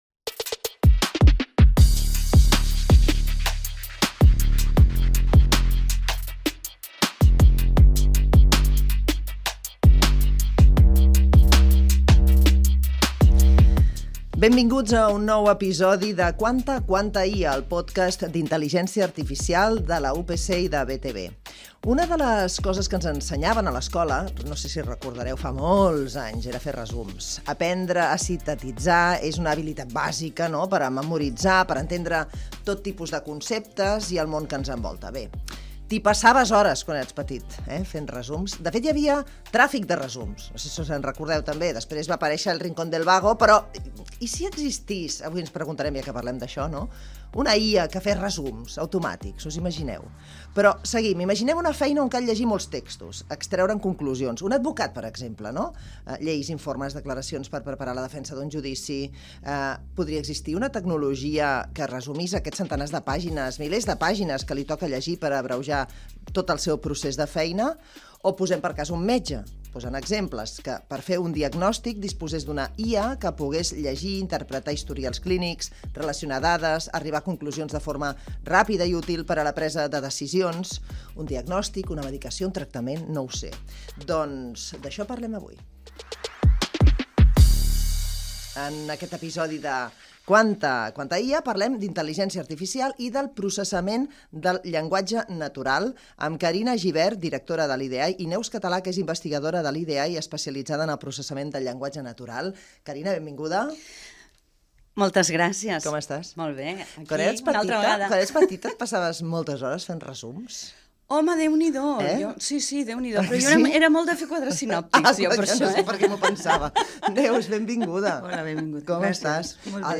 Totes dues analitzen com la IA està canviant la gestió de grans volums d’informació textual i com aquestes tecnologies poden ajudar professionals de diferents disciplines, des d’advocats a metges, a prendre decisions més ràpides i precises. Gènere radiofònic Divulgació